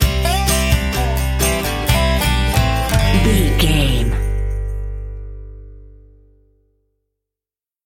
Ionian/Major
acoustic guitar
bass guitar
banjo